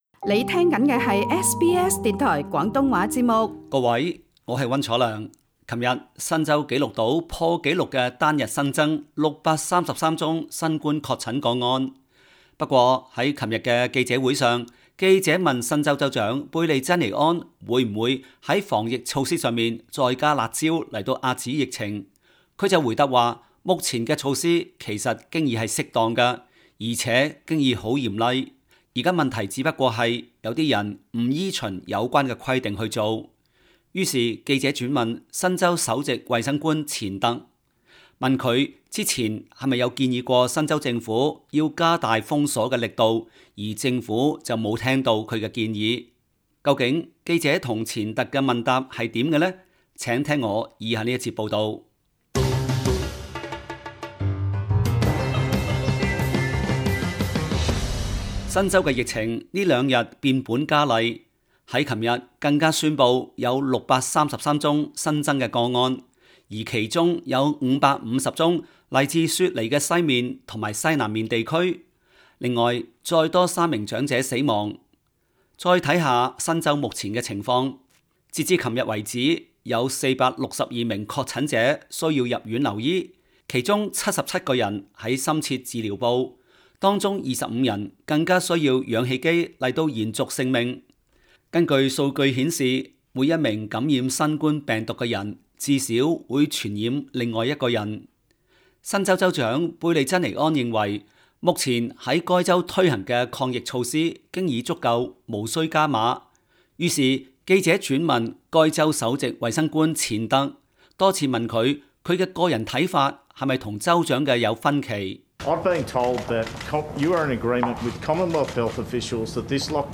新州州長貝莉珍妮安 (右) 和首席衛生官錢特 (中) 昨日聯袂出席新冠記者會。